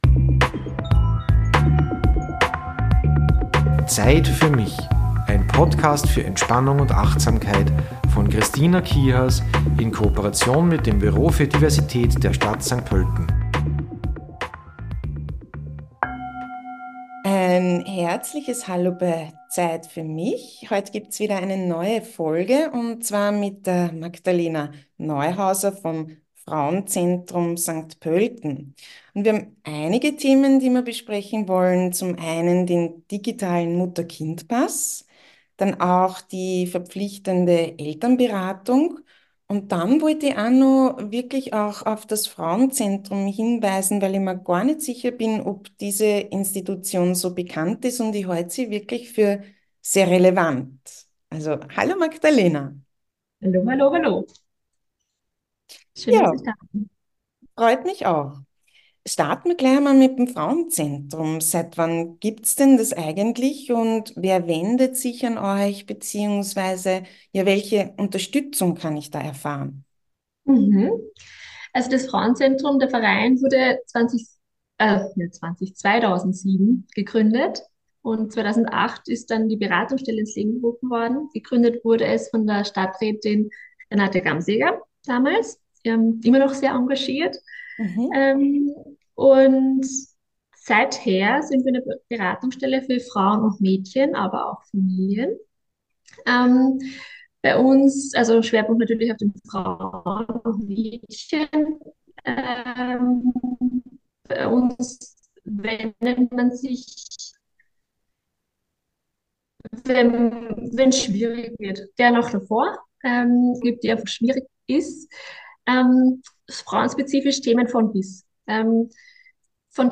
Durchaus kritisch betrachten wir politische und ökonomische Voraussetzungen, sprechen über Bildungskarenz und Pension, über Themen, die werdende Eltern beschäftigen. Im Entspannungsteil stelle ich dir die summende Bienenatmung vor.